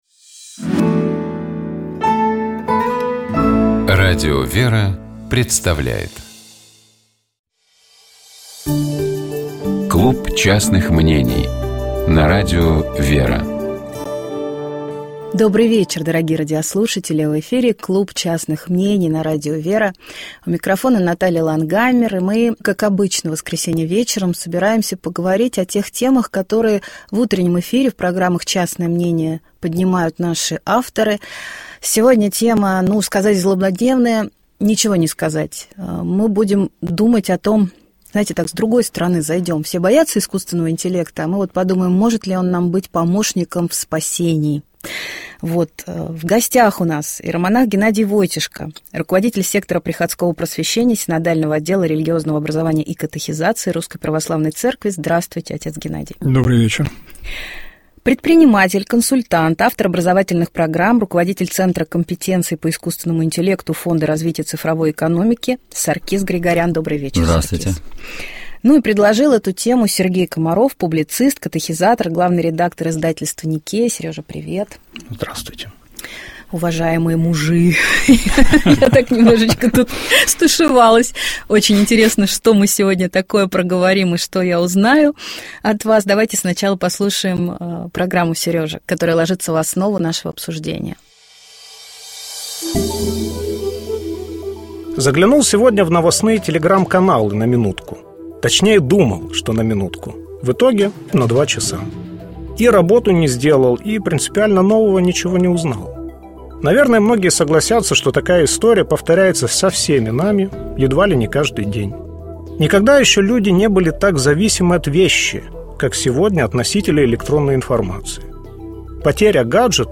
беседует со своими гостями